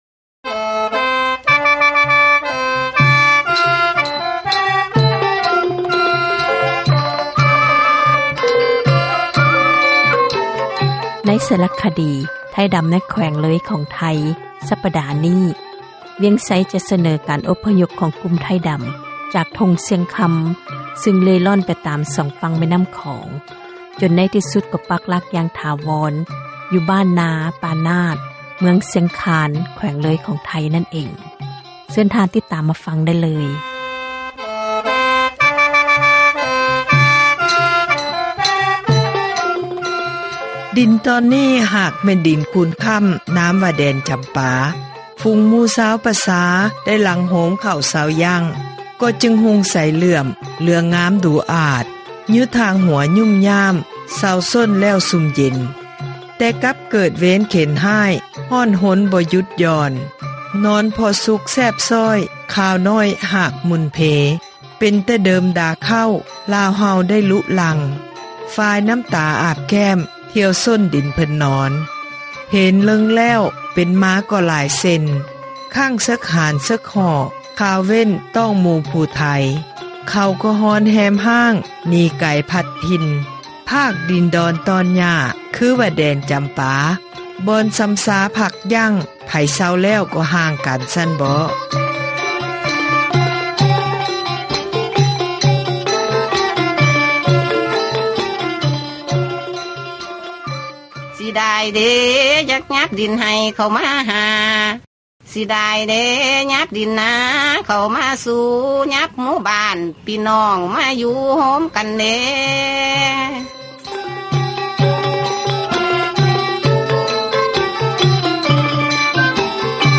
ຕໍ່ໄປແມ່ນ ຣາຍການ ສາຣະຄະດີ ປະຈຳ ສັປດາ ເຣຶ່ອງ ”ໄທດໍາທີ່ ເມືອງຊຽງຄານ” ຕອນທີ່ 4 ສເນີໂດຍ